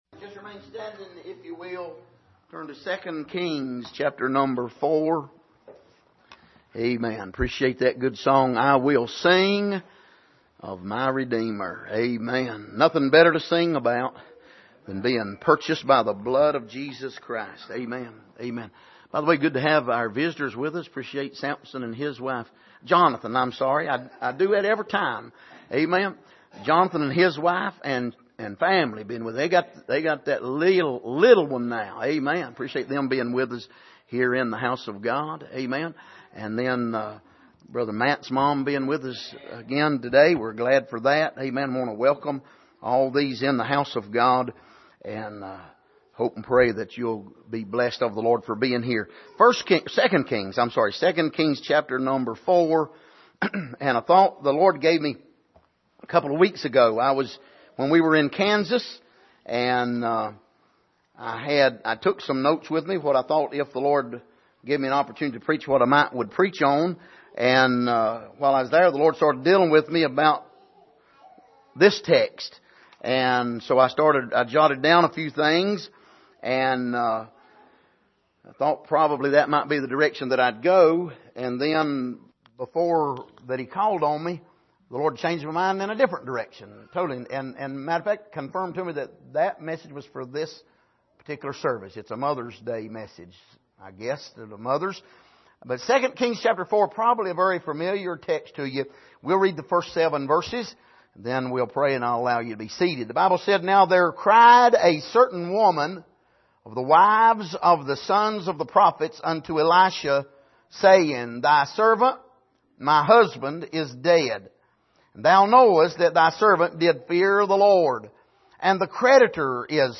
Passage: 2 Kings 4:1-7 Service: Sunday Morning